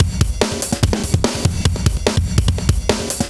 drums01.wav